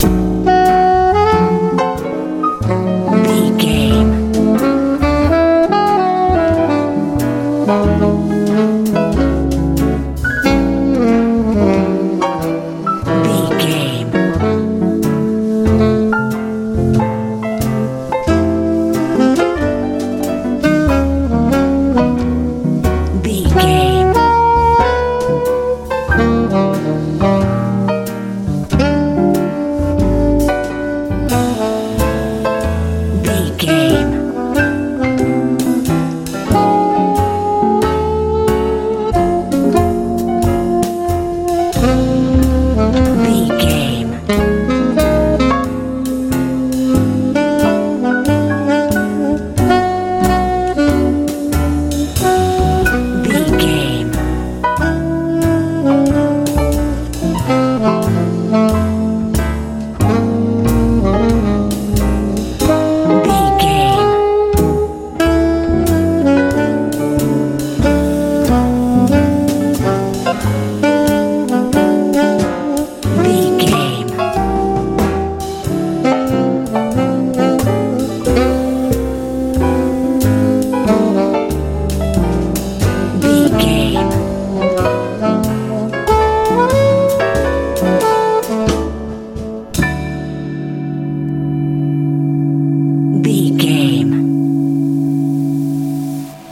jazz
Aeolian/Minor
light
mellow
bass guitar
drums
saxophone
electric guitar
piano
90s